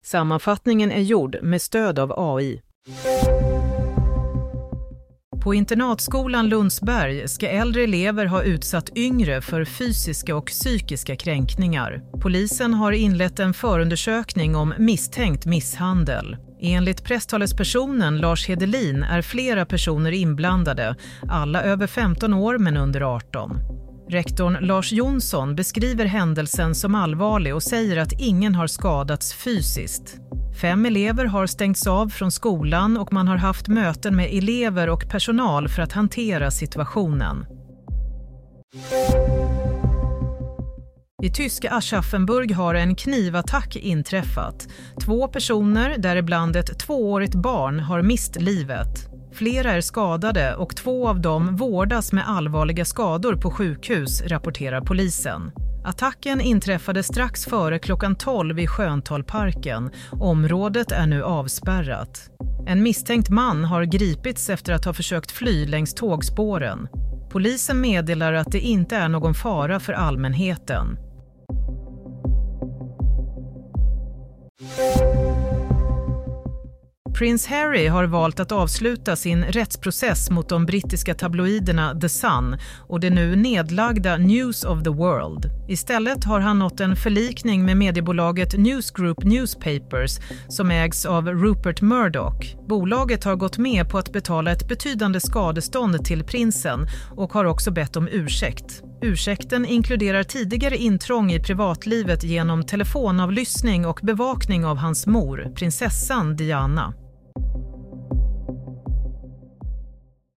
Nyhetssammanfattning – 22 januari 16.30